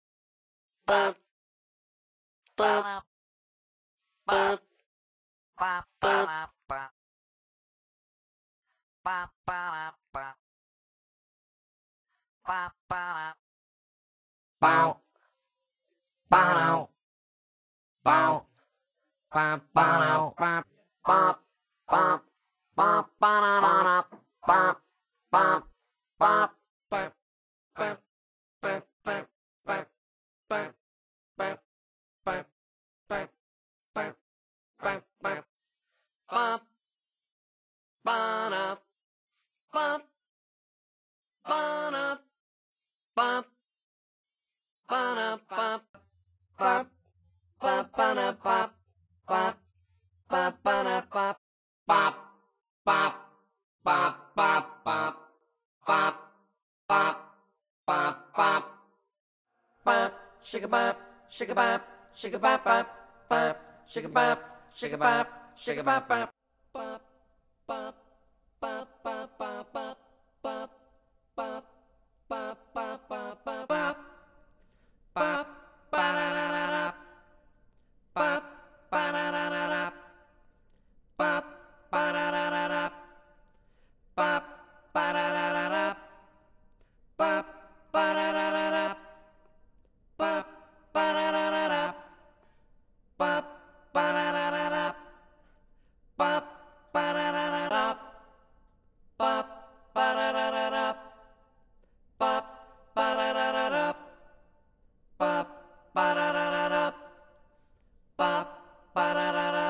Звук співу монстра